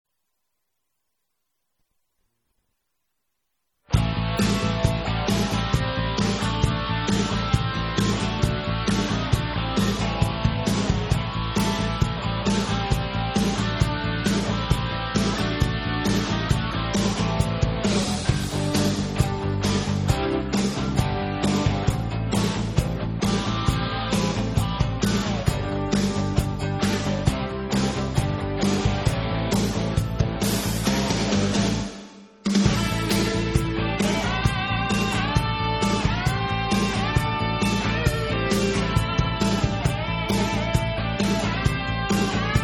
I believe it was battle music.